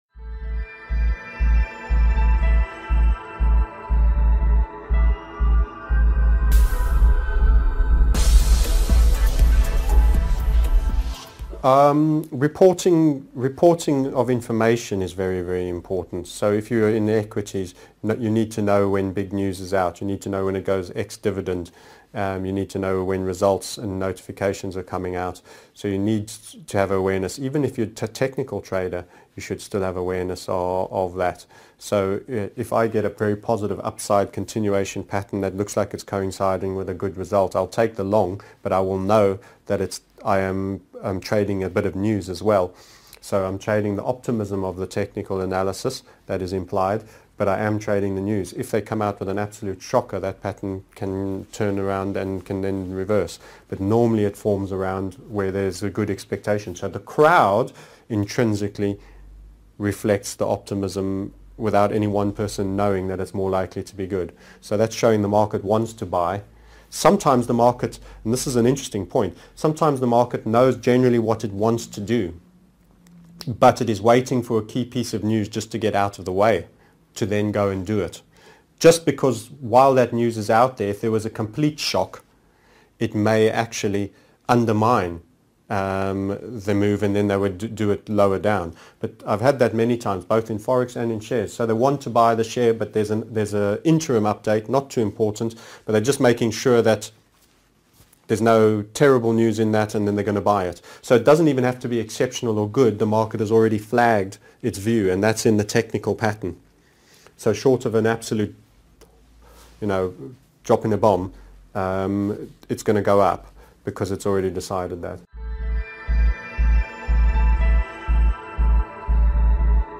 20 What are the most important parts in company documents and financials TMS Interviewed Series 20